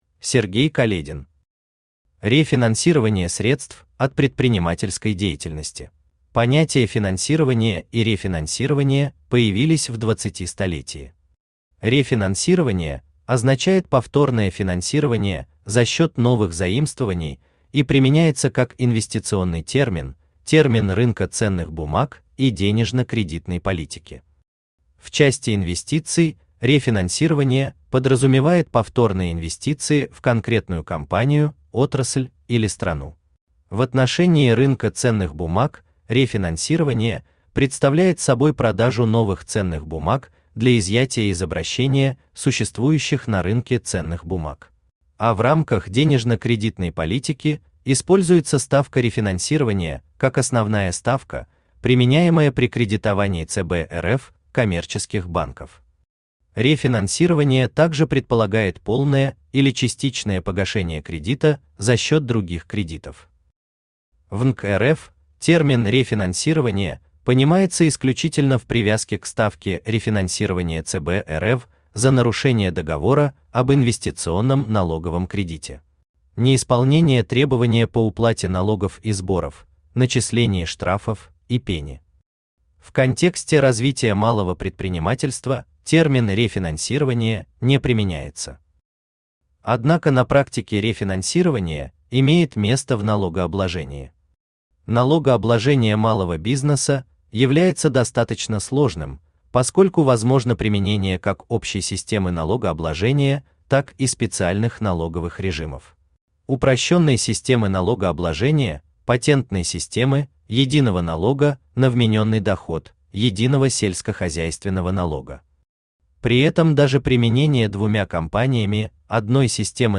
Аудиокнига Рефинансирование средств от предпринимательской деятельности | Библиотека аудиокниг